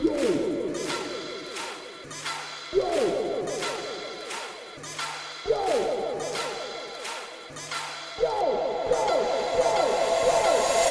描述：键是：E大调
标签： 88 bpm Trap Loops Synth Loops 1.84 MB wav Key : E
声道立体声